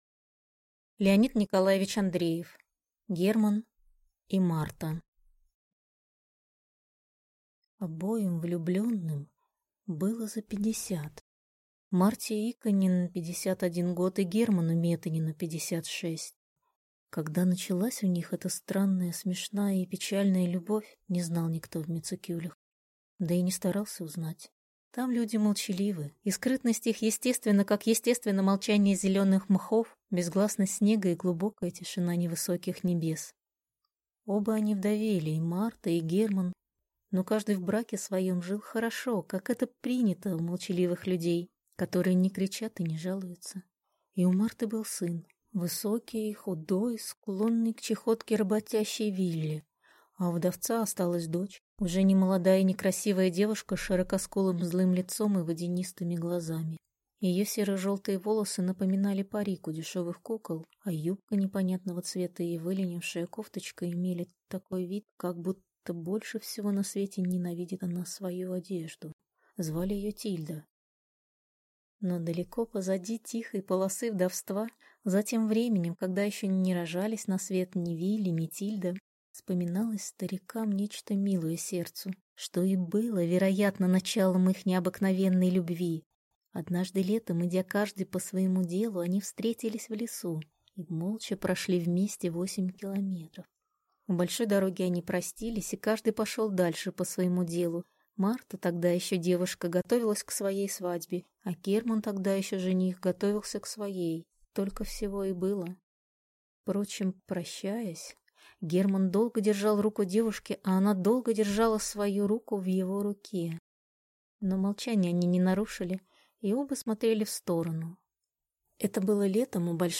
Аудиокнига Герман и Марта | Библиотека аудиокниг